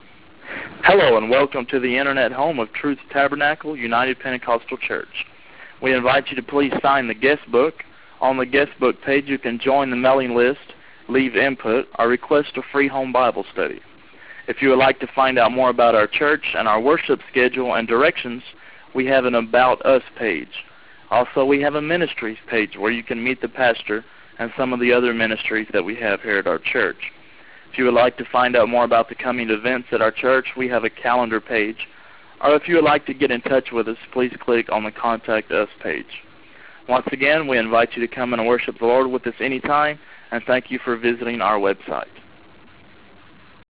Assistant Pastor